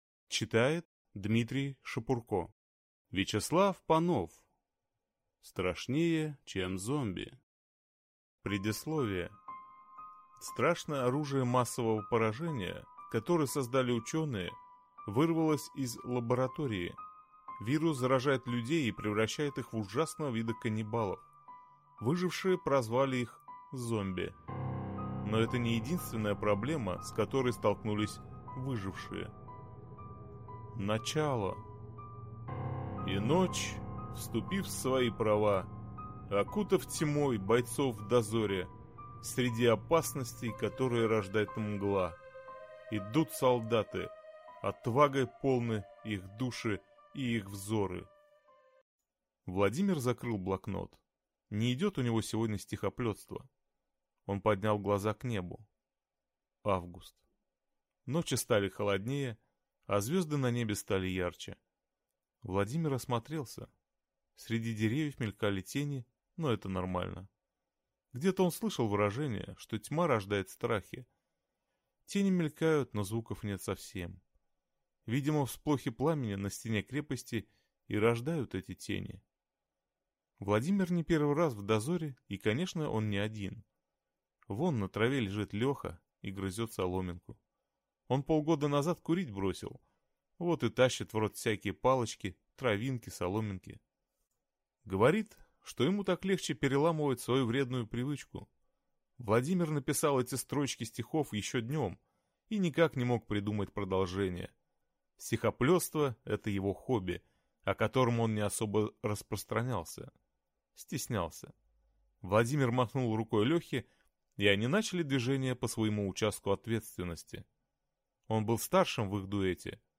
Аудиокнига Страшнее чем зомби | Библиотека аудиокниг